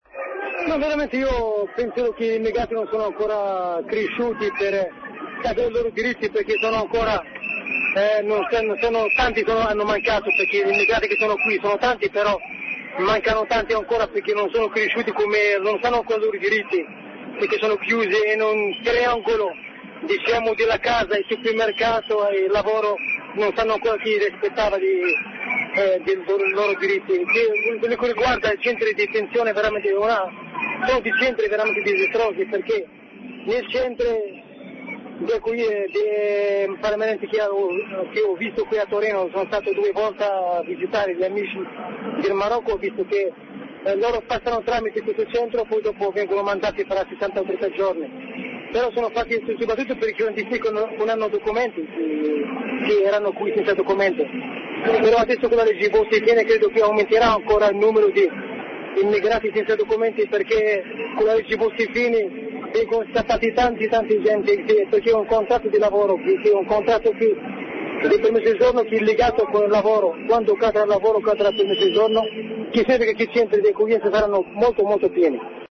Dal corteo di Torino, le voci dei migranti. Il loro rapporto con i diritti, con la legislazione italiana, con i centri di detenzione.